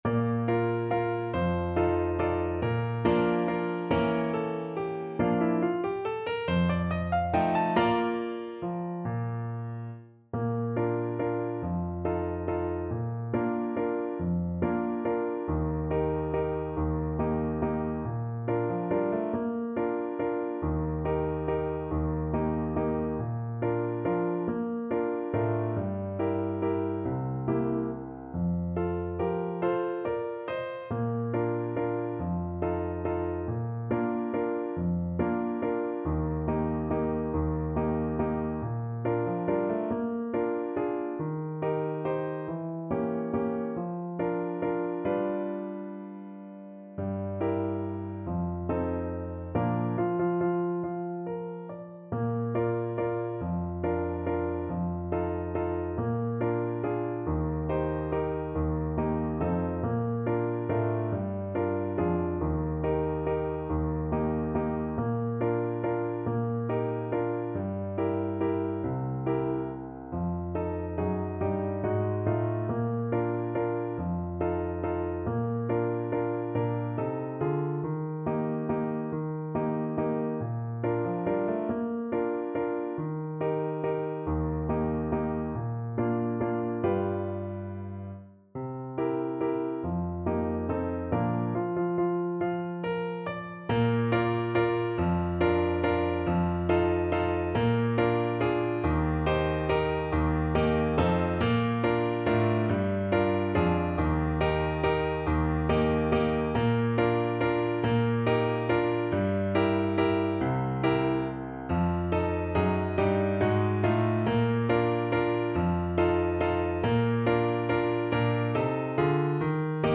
3/4 (View more 3/4 Music)
~ = 140 Tempo di Valse